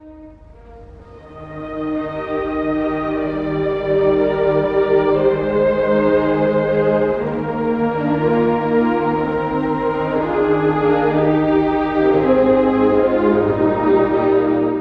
↑古い録音のため聴きづらいかもしれません！（以下同様）
Adagio
～ゆるやかに～
田園的。
第1楽章のAdagioとはまったく異なり、のんびりしています。
特に、クラリネットが活躍する楽章で、息の長い旋律が特徴的です。
また、最後のティンパニーによる主題の反復も印象的です。